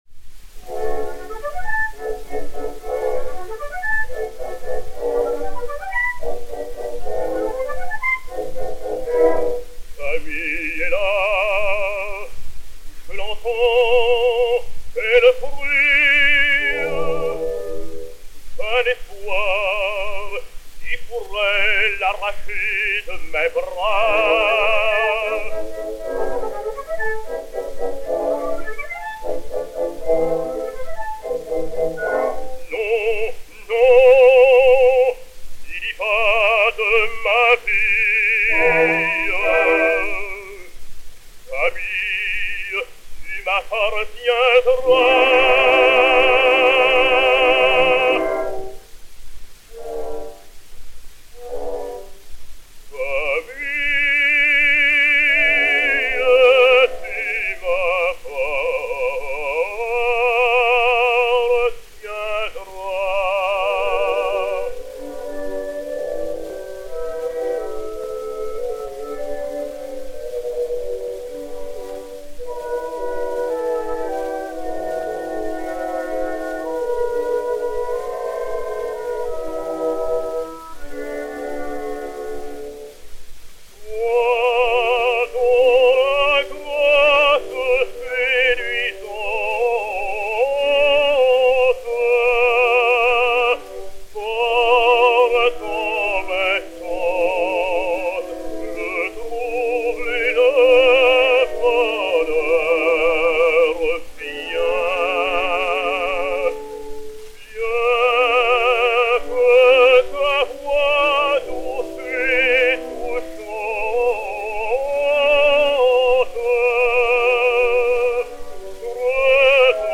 baryton français
et Orchestre